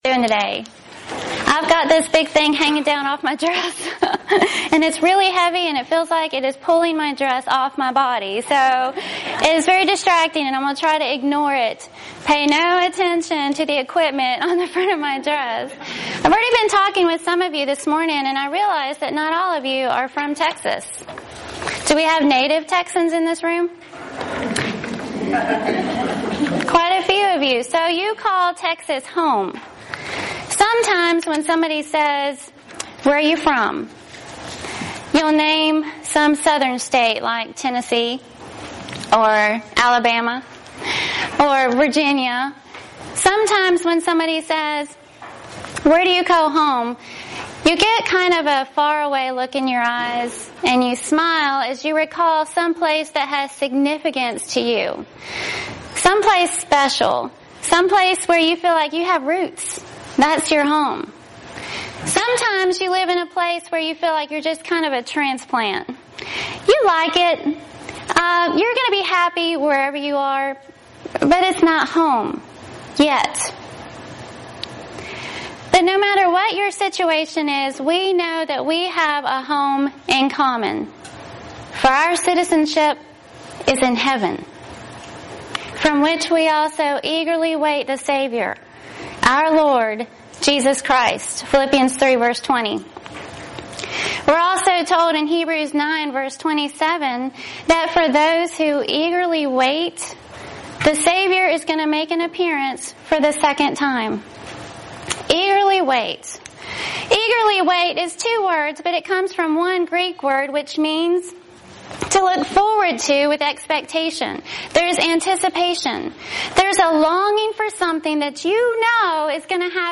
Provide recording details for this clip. Event: 31st Annual Southwest Lectures